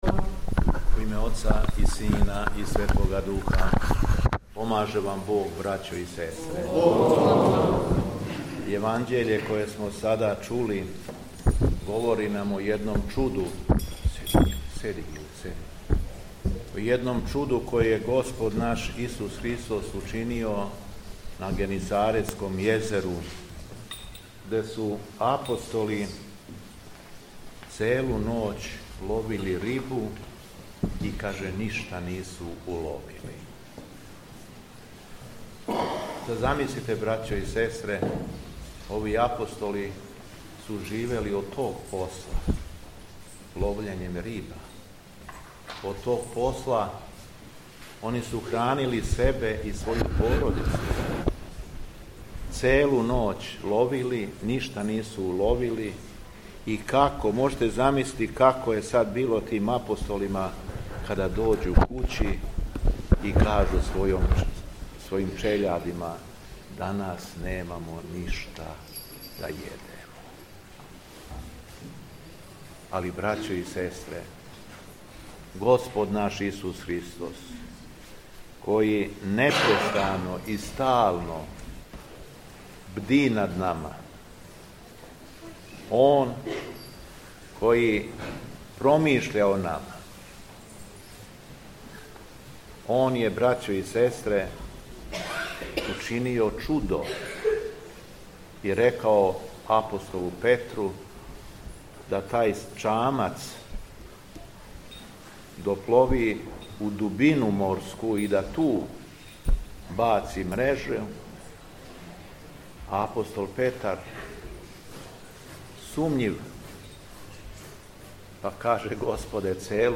СВЕТА АРХИЈЕРЕЈСКА ЛИТУРГИЈА У ЈОВЦУ КОД ЋУПРИЈЕ
Беседа Његовог Високопреосвештенства Митрополита шумадијског г. Јована
У Недељу 18. по Духовима, 12. октобра 2025. године, када се наша Света Црква молитвено сећа Преподобног оца нашег Киријака Отшелника, Његово Високопреосвештенство Архиепископ крагујевачки и Митрополит шумадијски Господин Јован служио је Свету архијерејску Литургију у ћупријском селу Јовцу у Архијерејском намесништву Беличком.